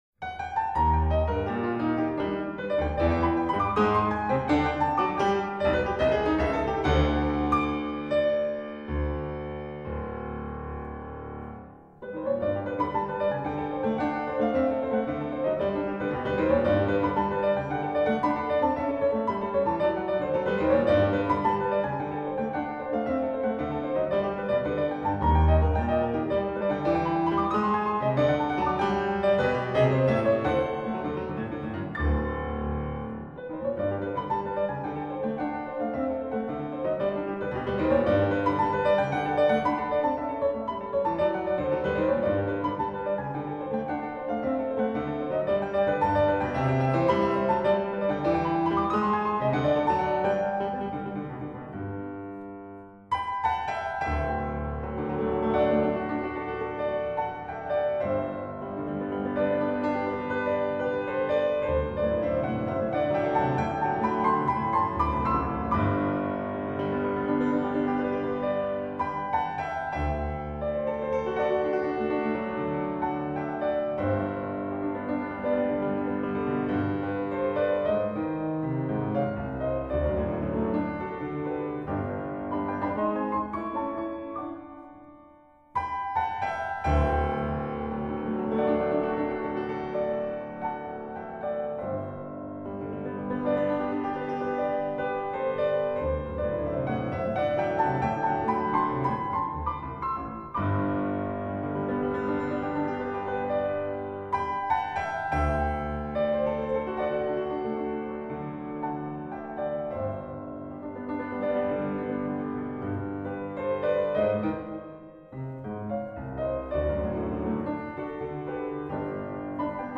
Estudo-Choro, pleno de humor, para evitar, mercê da modéstia do ilustre colega, uma negação.